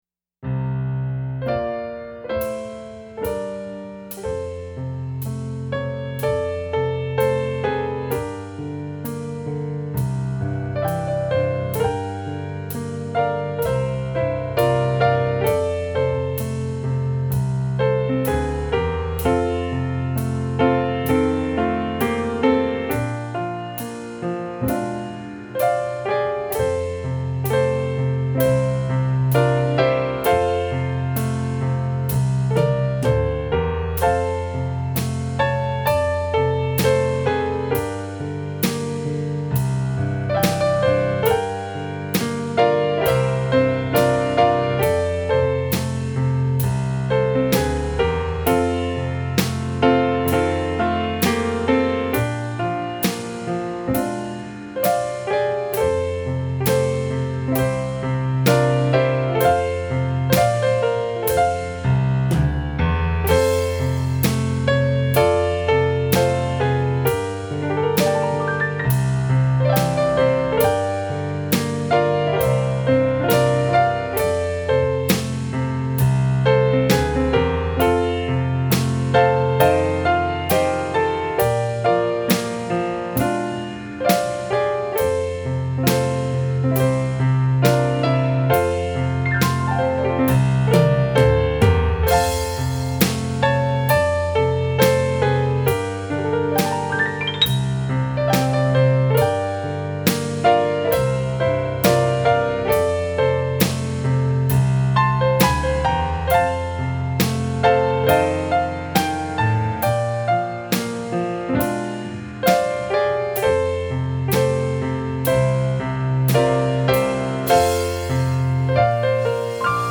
DIGITAL SHEET MUSIC -PIANO SOLO
Country Gospel, Worship Piano Solos